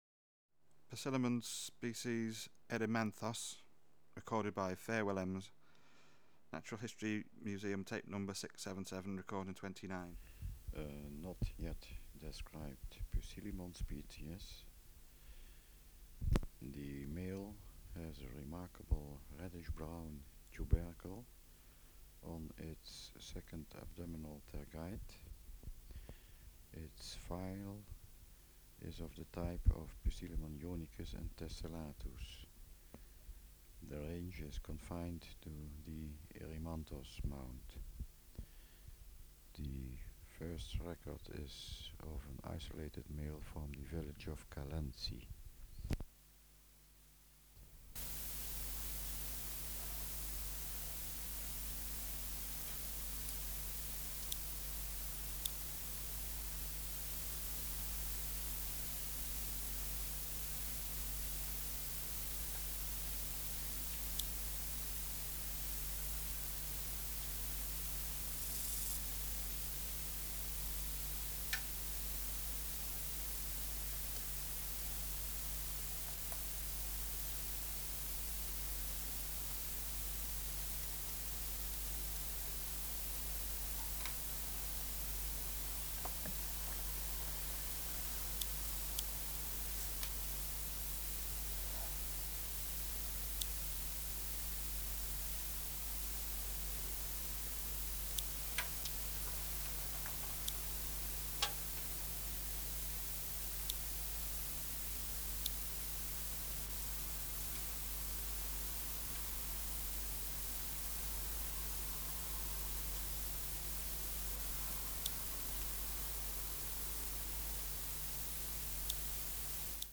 Natural History Museum Sound Archive Species: Poecilimon 'erimanthos'